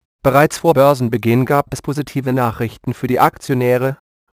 Texte de d�monstration lu par Reiner (AT&T Natural Voices; distribu� sur le site de Nextup Technology; homme; allemand)
Monochrome Web vous propose d'�couter, via le lien ci-dessous, la d�monstration audio de : Reiner (AT&T Natural Voices; distribu� sur le site de Nextup Technology; homme; allemand)...